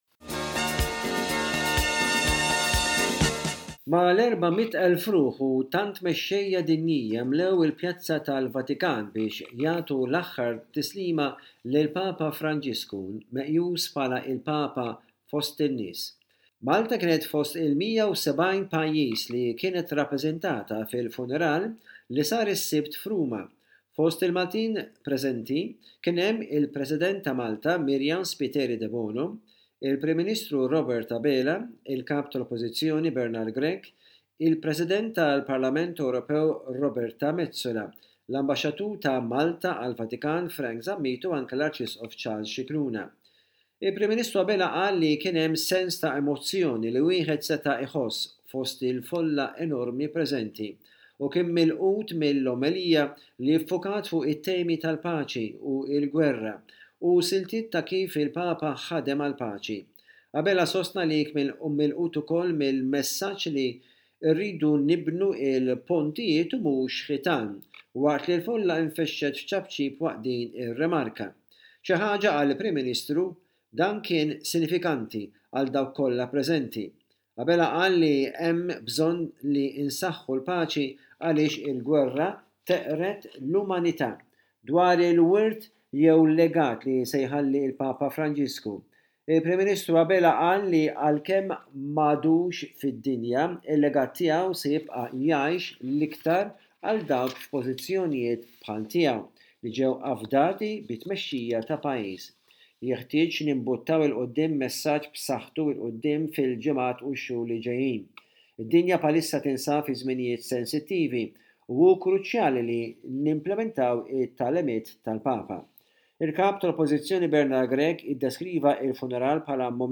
Rapport